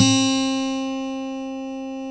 Index of /90_sSampleCDs/Roland L-CD701/GTR_Steel String/GTR_18 String
GTR 6-STR20X.wav